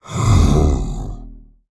Minecraft Version Minecraft Version snapshot Latest Release | Latest Snapshot snapshot / assets / minecraft / sounds / mob / ravager / idle3.ogg Compare With Compare With Latest Release | Latest Snapshot